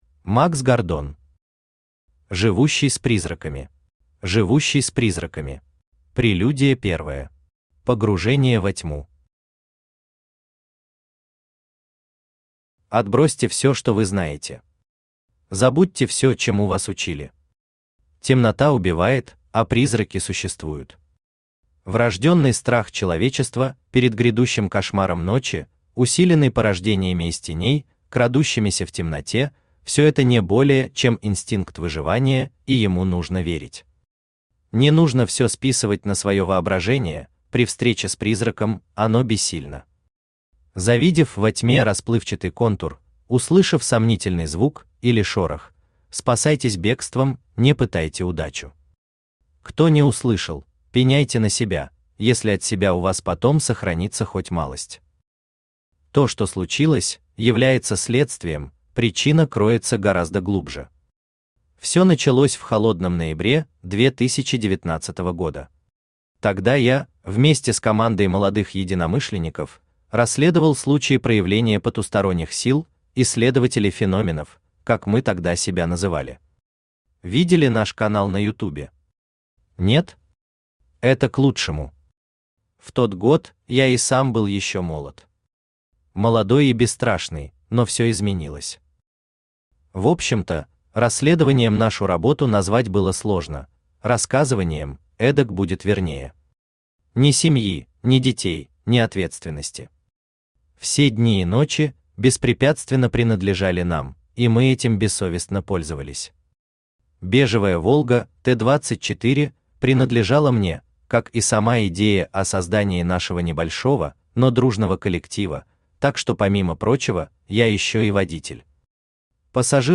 Аудиокнига Живущий с призраками | Библиотека аудиокниг
Aудиокнига Живущий с призраками Автор Макс Гордон Читает аудиокнигу Авточтец ЛитРес.